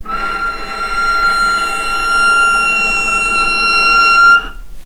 vc-F6-mf.AIF